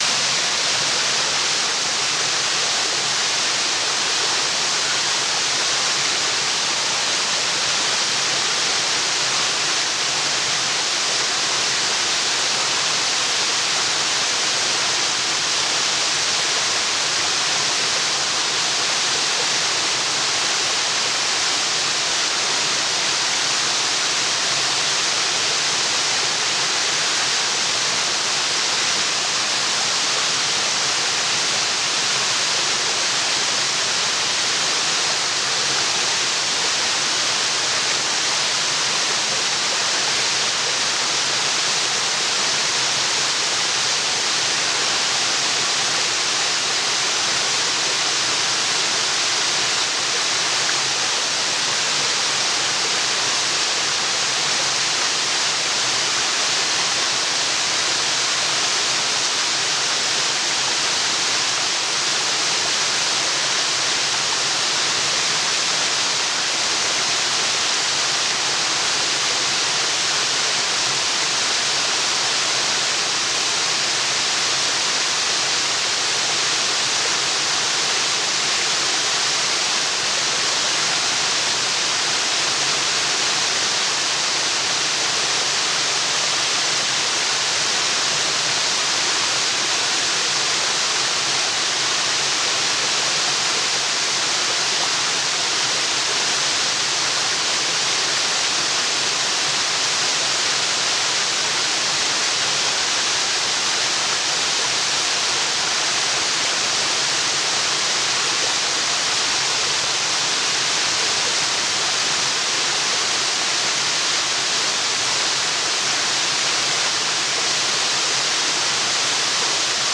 60-SEKUNDEN-ENTSPANNUNG: Wildbach-Meditation mit fließendem Wasser